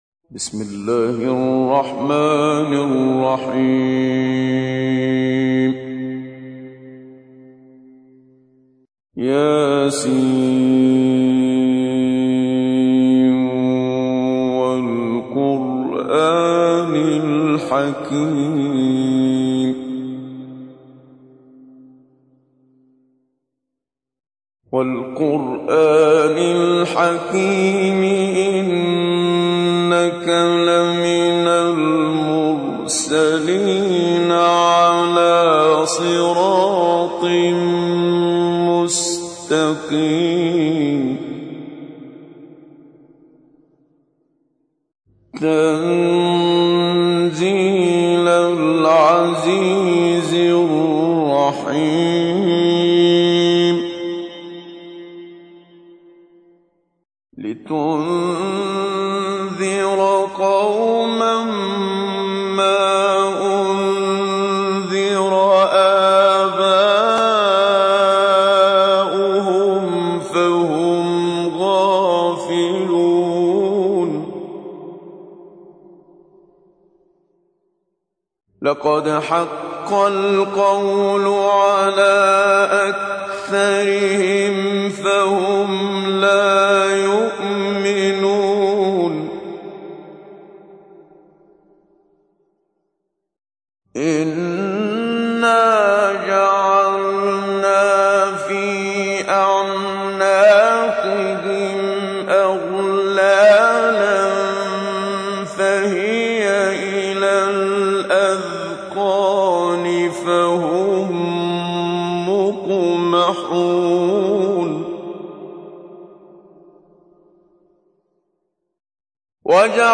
تحميل : 36. سورة يس / القارئ محمد صديق المنشاوي / القرآن الكريم / موقع يا حسين